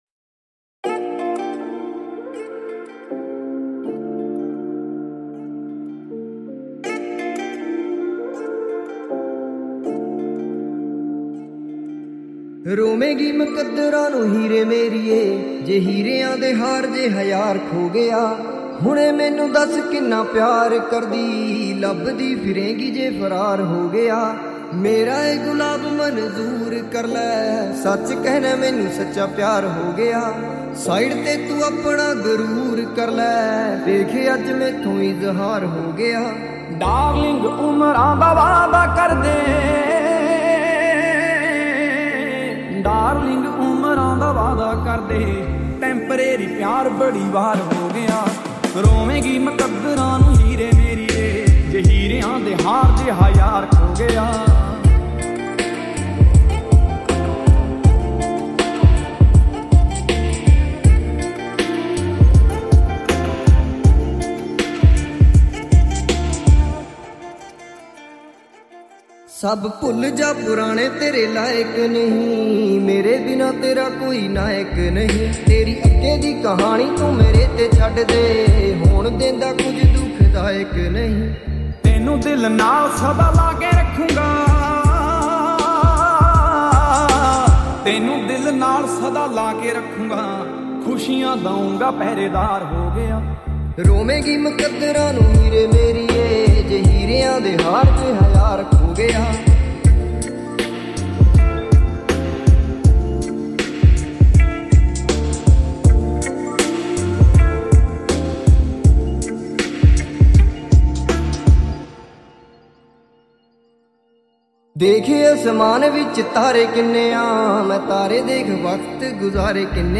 Genre - Classical